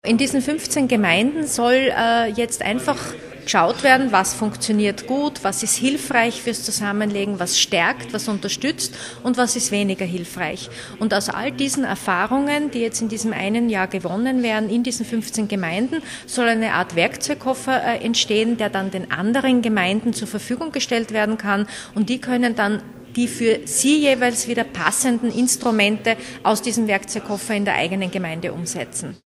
O-Ton: Partnerschaften mit dem Integrationsressort
Integrationslandesrätin Bettina Vollath: